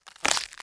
BREAK_Squishy_stereo.wav